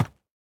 Minecraft Version Minecraft Version snapshot Latest Release | Latest Snapshot snapshot / assets / minecraft / sounds / mob / goat / step3.ogg Compare With Compare With Latest Release | Latest Snapshot
step3.ogg